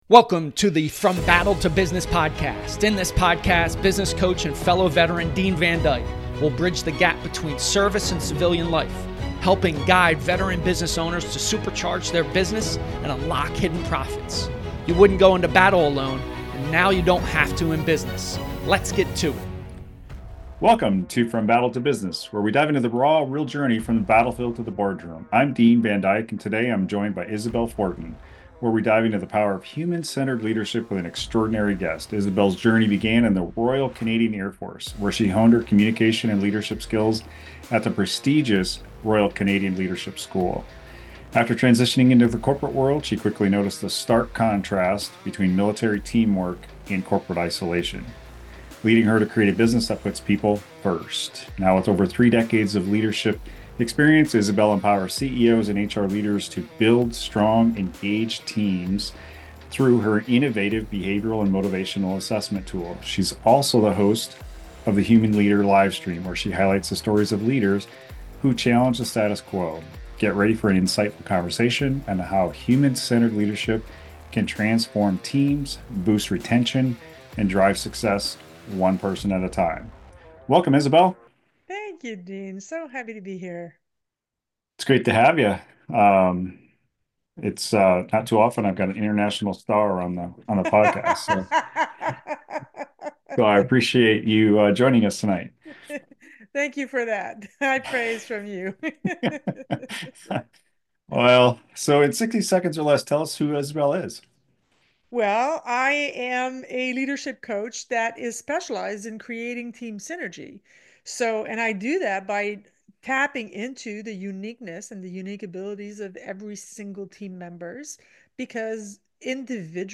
This episode is packed with insights on leadership, retention, and building a thriving workplace culture . If you’re a leader looking to level up your approach to team management, you won’t want to miss this conversation!